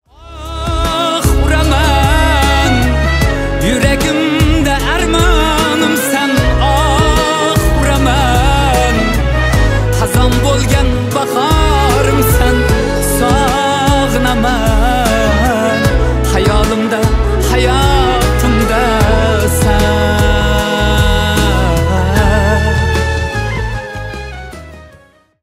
Узбекские # Мусульманские